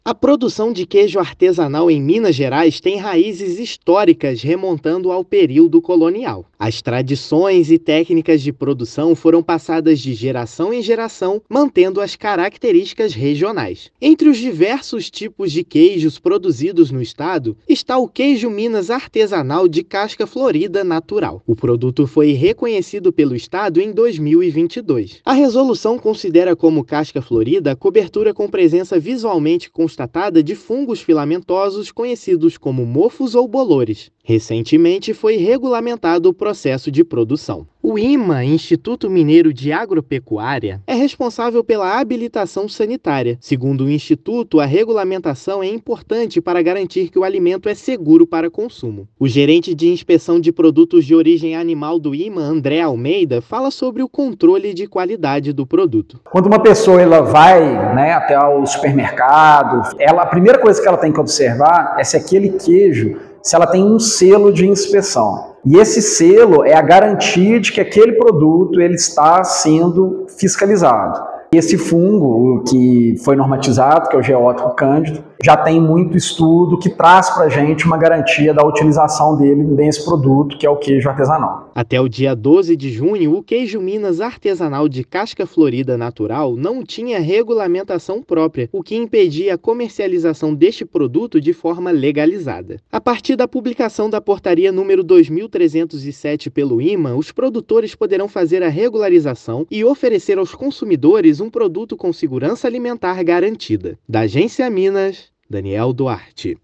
Entenda a importância das normas estabelecidas pela legislação para a produção e comercialização do Queijo Minas Artesanal de Casca Florida. Ouça a matéria de rádio: